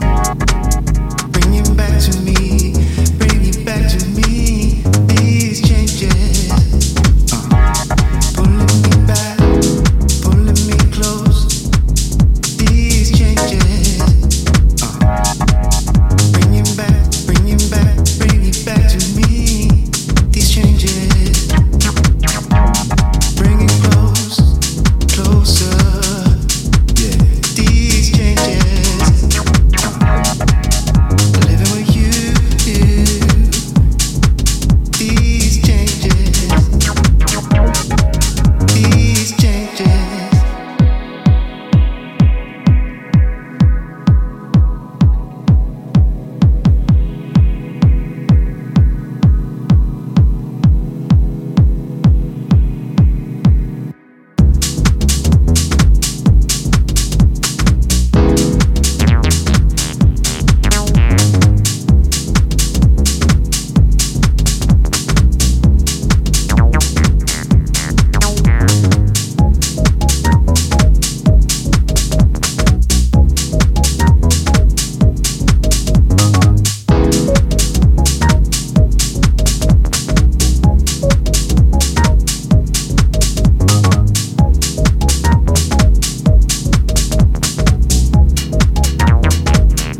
ジャンル(スタイル) DEEP HOUSE / HOUSE